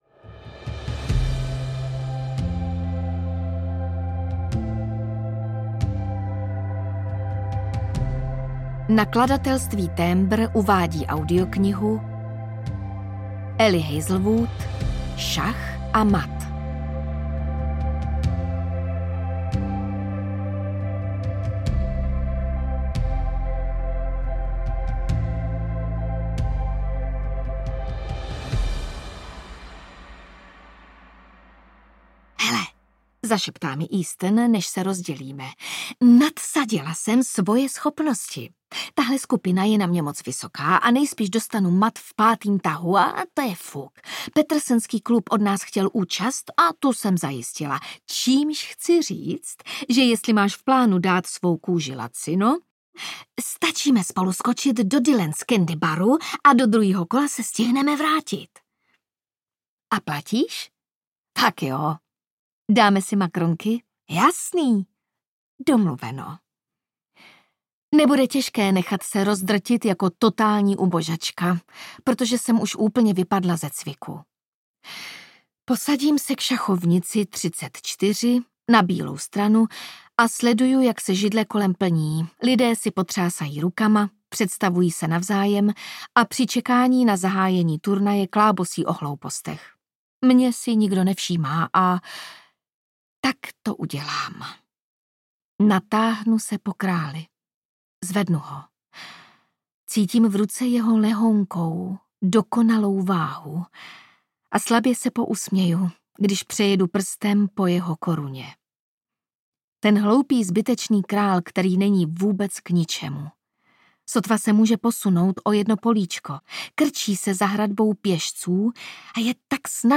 Šach a mat audiokniha
Ukázka z knihy